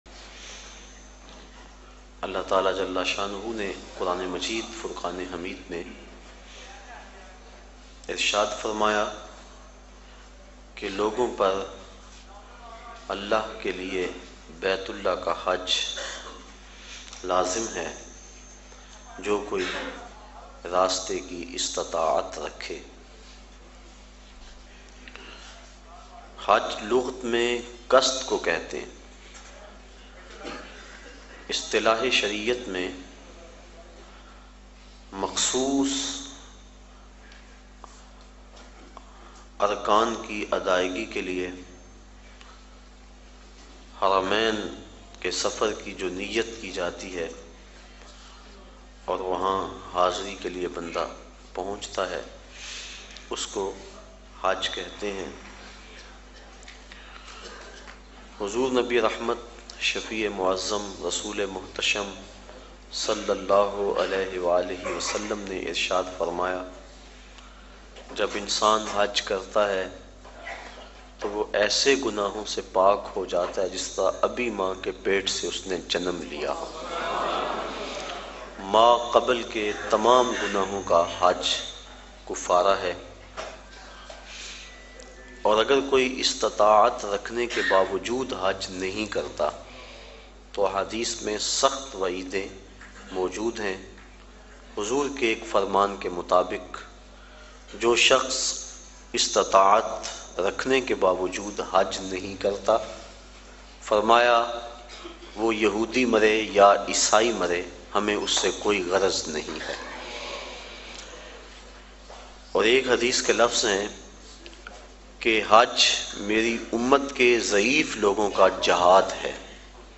Hajj Ki Fazeelat Bayan mp3